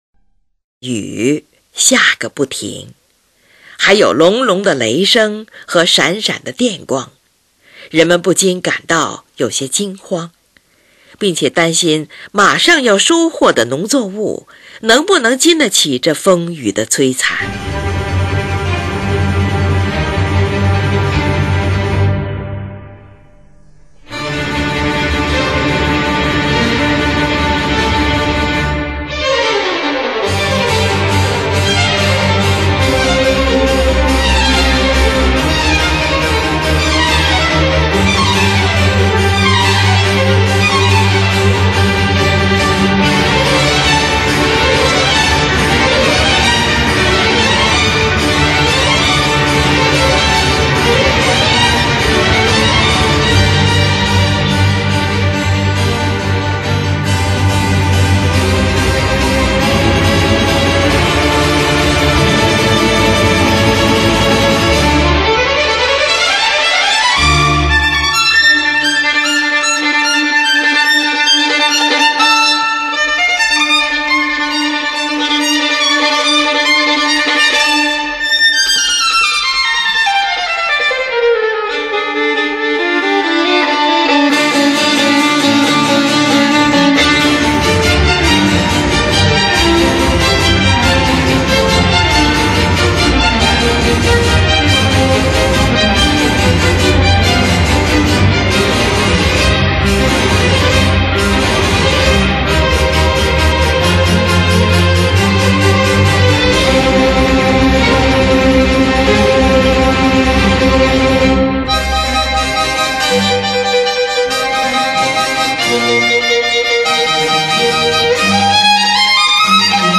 夏天--g小调
5次合奏中插入4次主奏的复奏形式，
开头合奏表现人们惊呼恐惧，空中雷声隆隆、电光闪闪、冰雹纷纷落地摧残农作物。